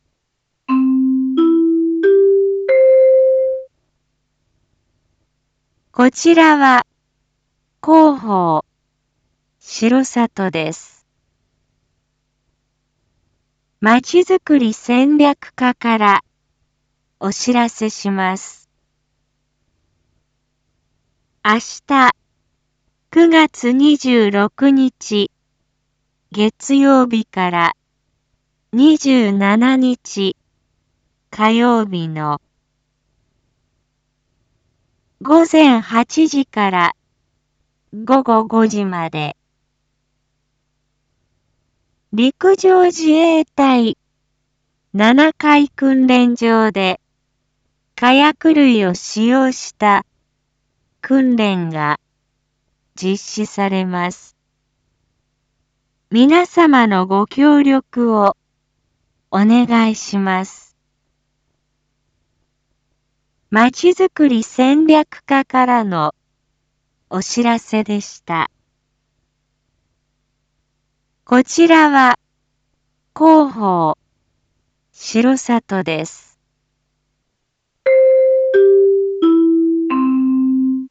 一般放送情報
Back Home 一般放送情報 音声放送 再生 一般放送情報 登録日時：2022-09-25 19:01:22 タイトル：R4.9.25 19時放送分 インフォメーション：こちらは広報しろさとです。